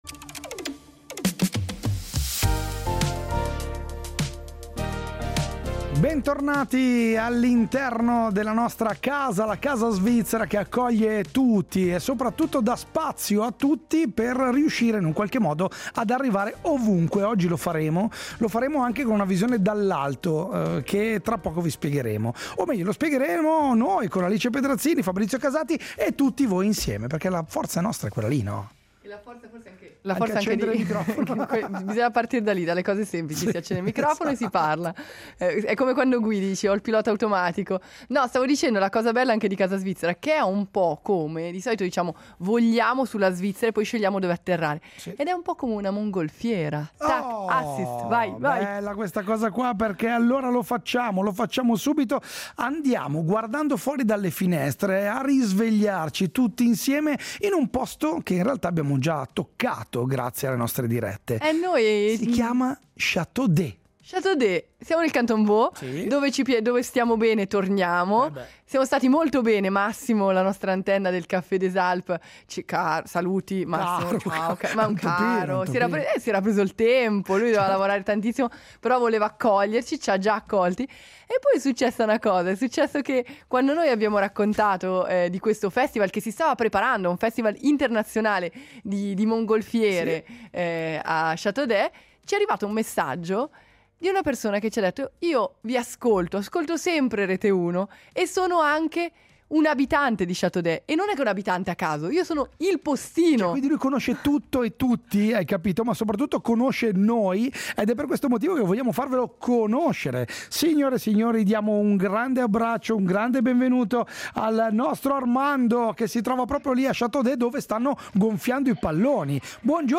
Casa Svizzera si è risvegliata di nuovo a Château-d’Oex, nel Canton Vaud, mentre il cielo si riempiva di mongolfiere e il Festival entrava nel vivo.